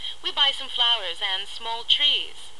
英語發音 English Pronunciation
(加連線者為連音，加網底者不需唸出聲或音很弱。)